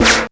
RAYZ FX.wav